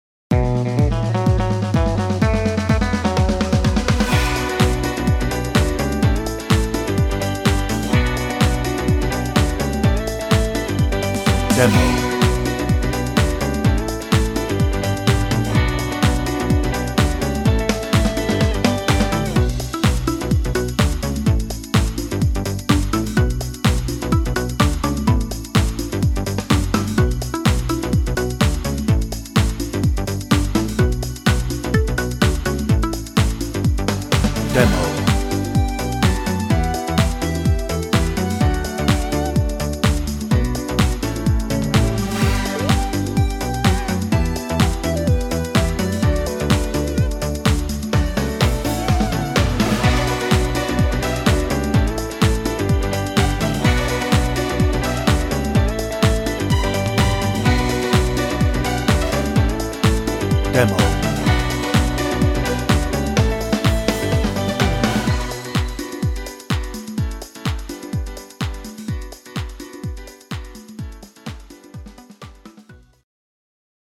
Hoedown - No ref vocal
Instrumental